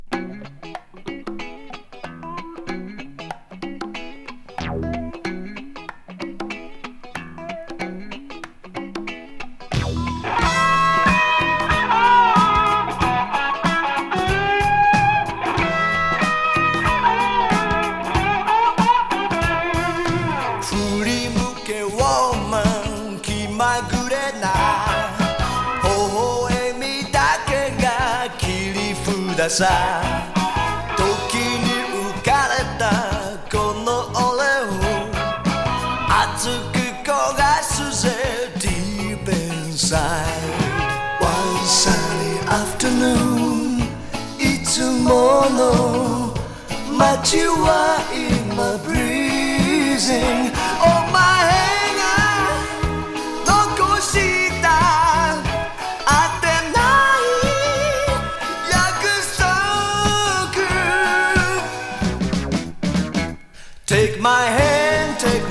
日本のロックバンド
イントロのカッティングギターがかっこいい、、、。Jspsnese Funky Rock！